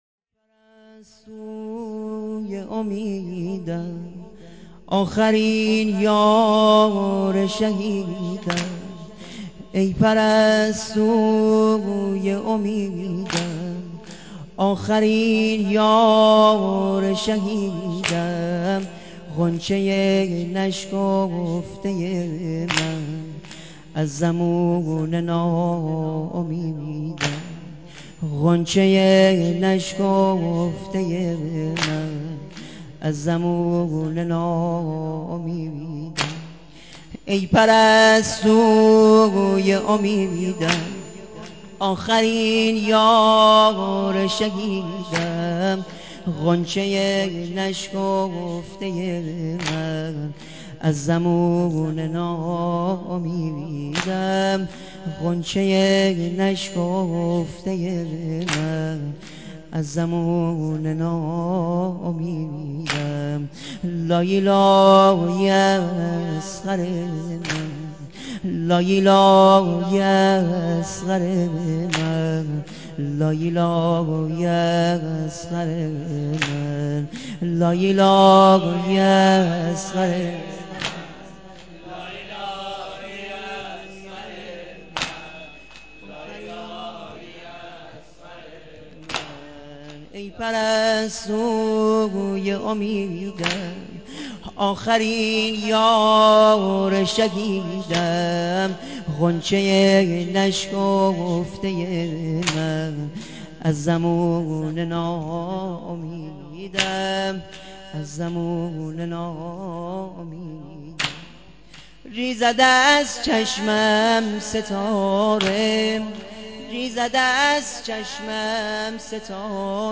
نوحه سینه زنی